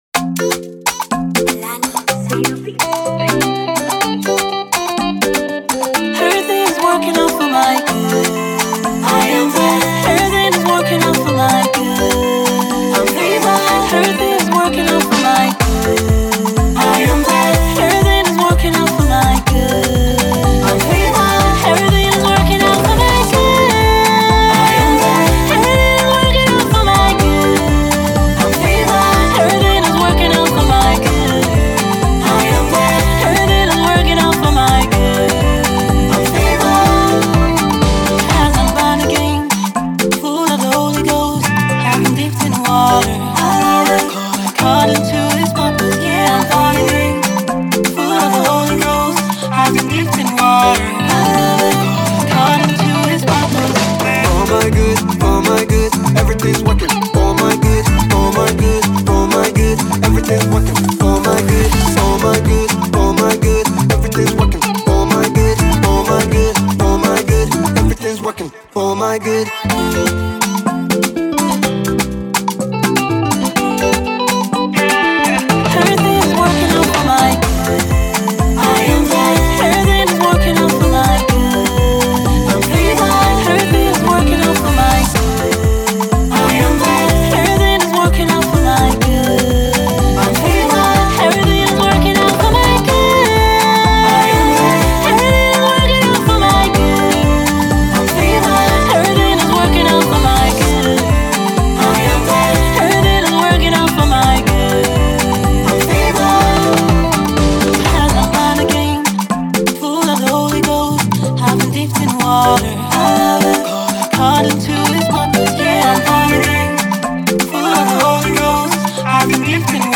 Gospel music duo